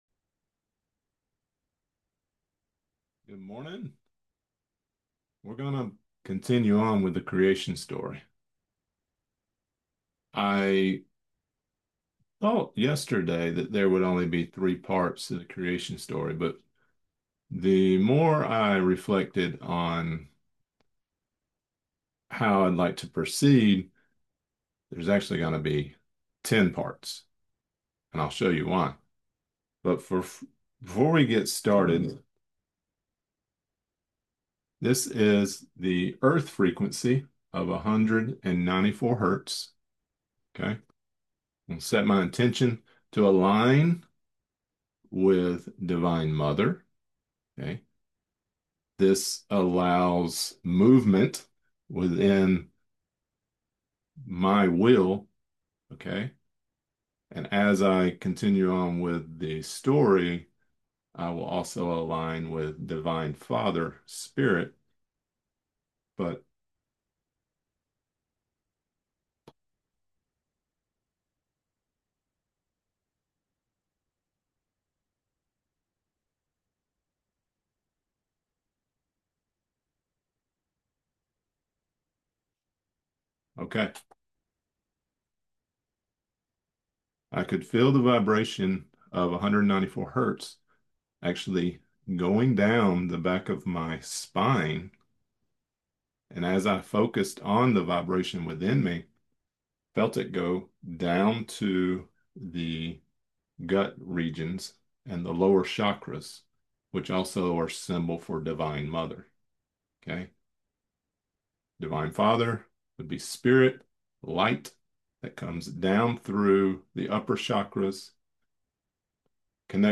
Lecture Created Transcript Blockchain Transcript 12/02/2025 Audio Only 12/02/2025 Watch lecture: Visit the Cosmic Repository video site .